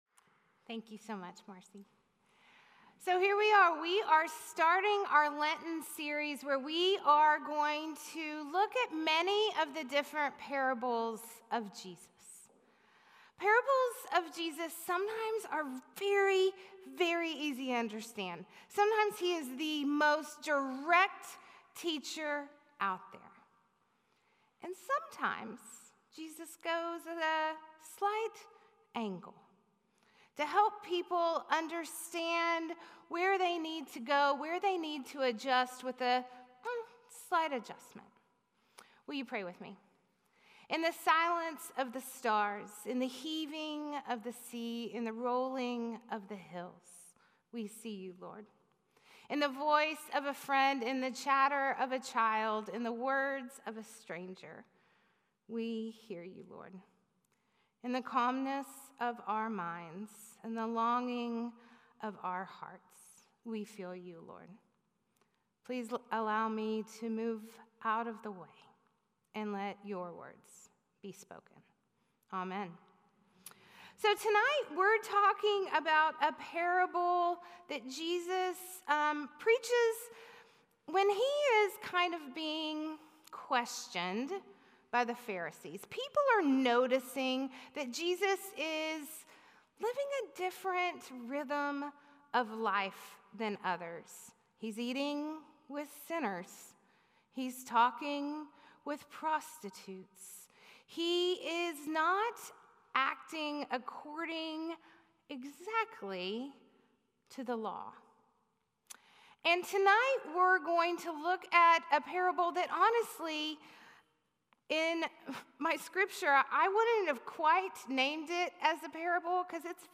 A message from the series "Jesus Parables."